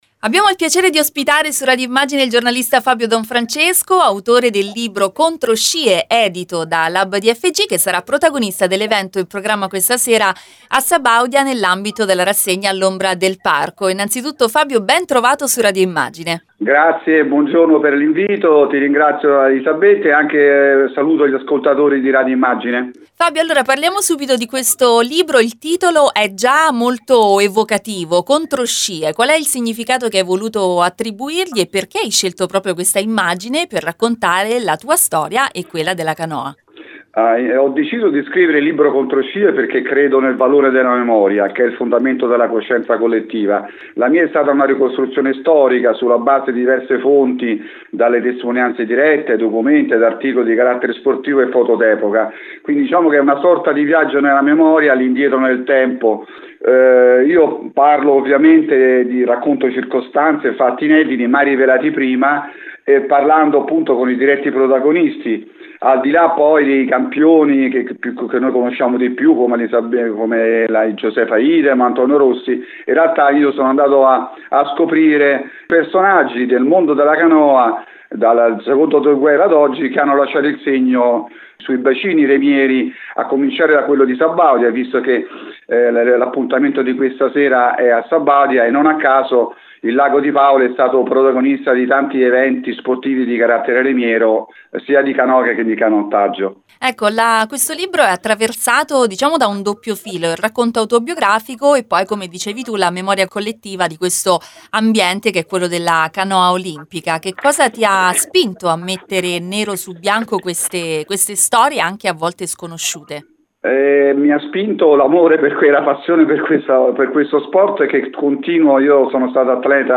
Ascolta l’intervista all’autore: